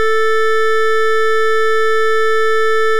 OPL waveforms
Abs-sine – every other cycle only – a.k.a. camel sine. Waveform 5. Zoom out 2x, while doubling the frequency of the non-padding part; then zoom in 2x after to remove the negative half from both cycles.